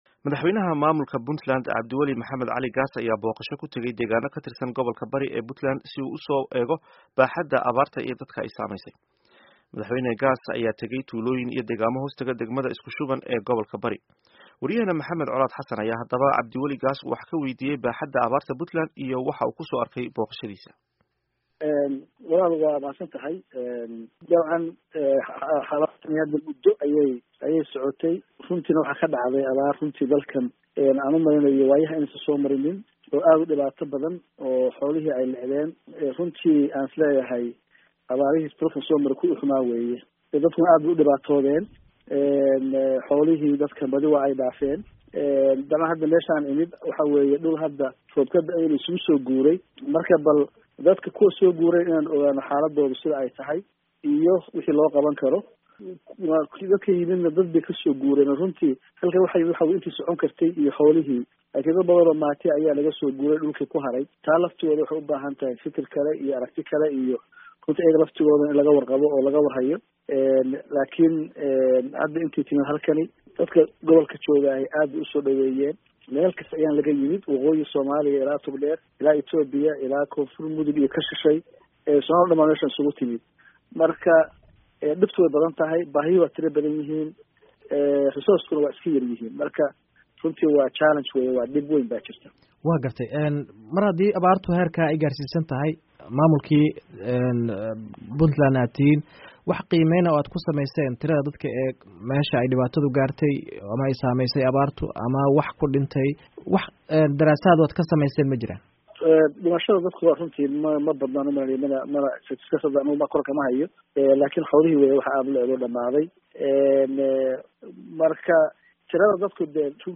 Wareysi: Cabdi Weli Gaas